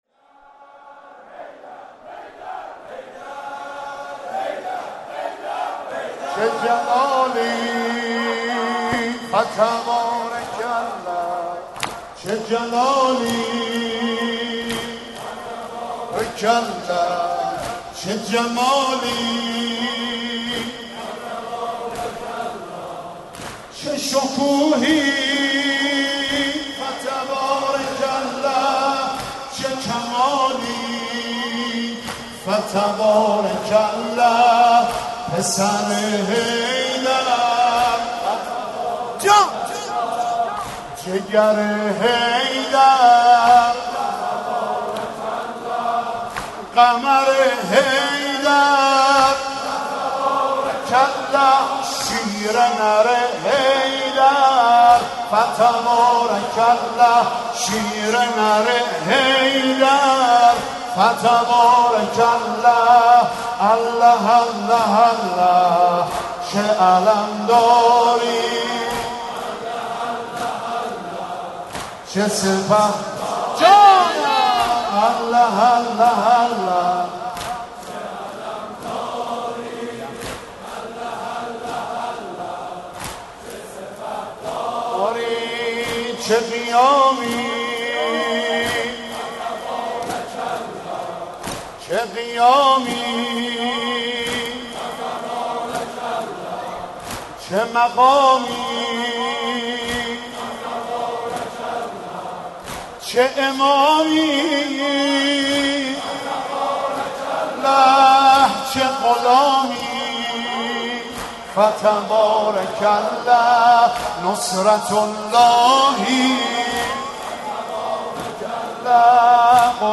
عـزاداری در حـرم سیـد الکریم علیه السلام